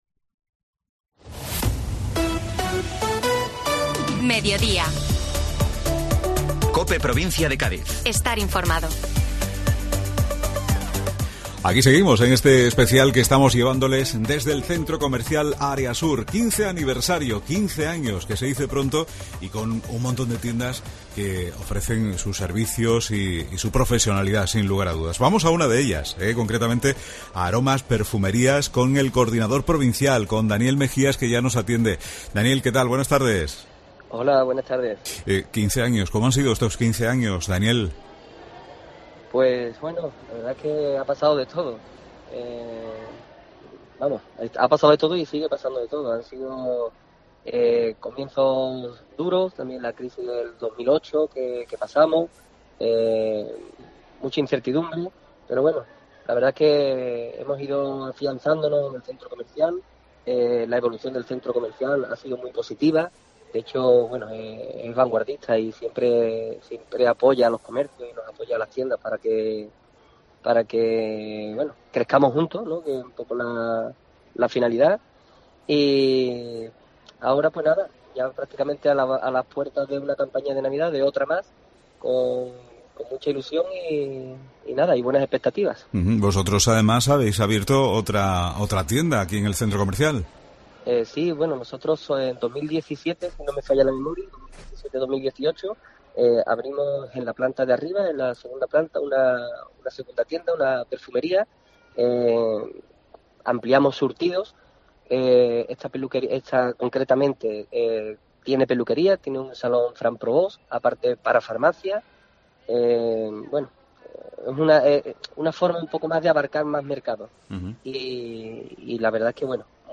Herrera en COPE Provincia de Cádiz y Mediodía COPE Provincia de Cádiz desde Centro Comercial Área Sur en la celebración de su 15 aniversario.